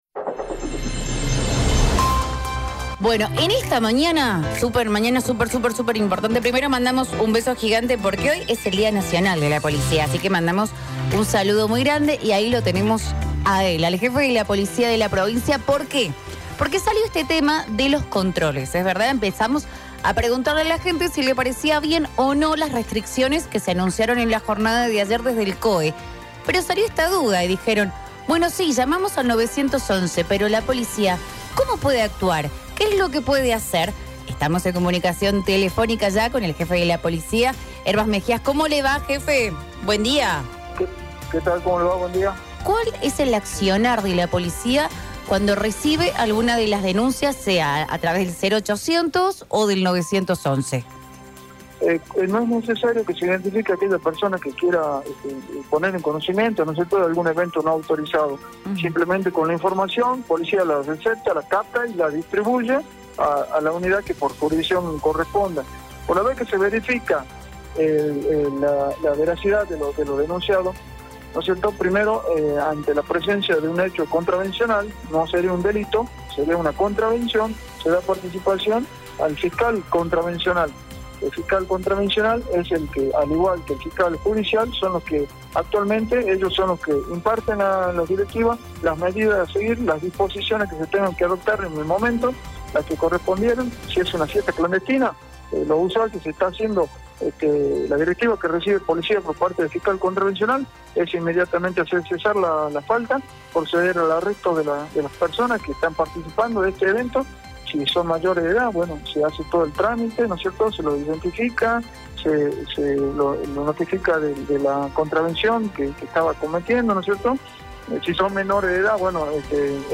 JEFE POLICIA - MEJIAS.mp3
El jefe de la policía de la provincia de Jujuy, Horacio Herbas Mejías, comentó sobre el accionar de las fuerzas de seguridad ante los reiterados llamados que se reciben diariamente, en especial los fines de semana con denuncias de fiestas clandestinas.